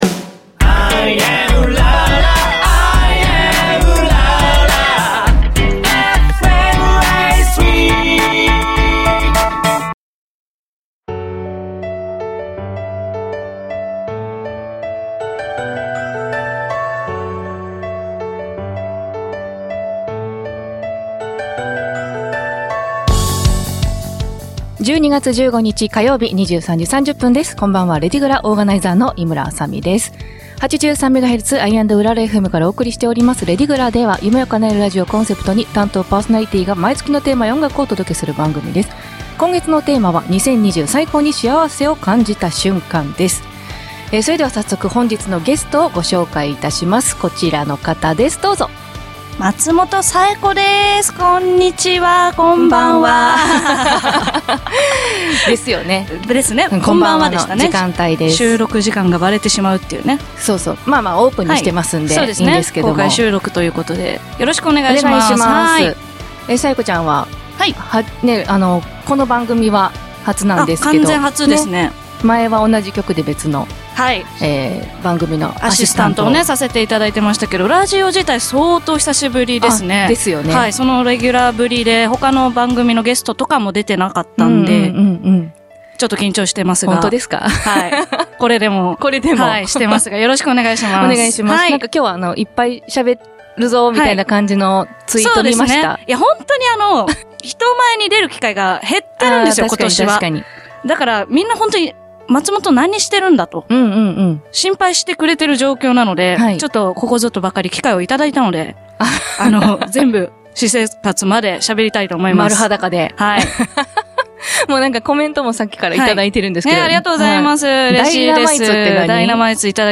また、番組の冒頭と最後に毎月全４週で完結するラジオドラマを放送。エンディング曲は毎月変わります。